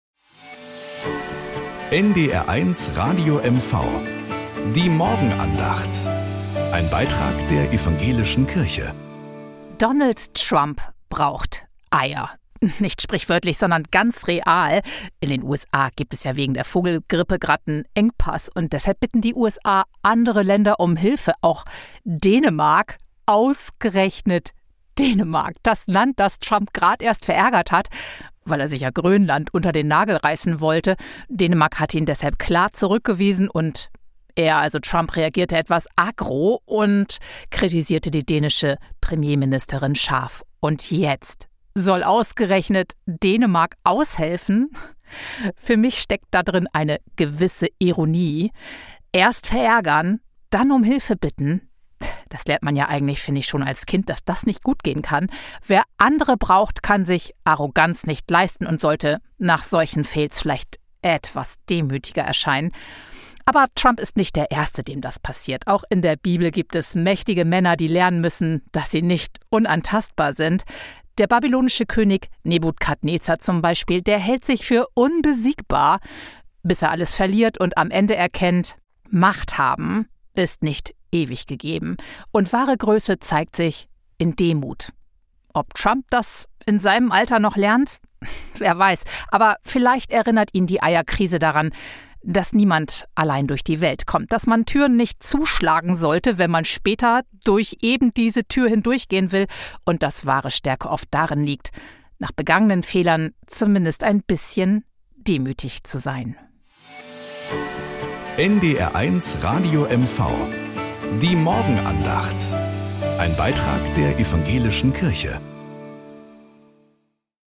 Nachrichten aus Mecklenburg-Vorpommern - 05.05.2025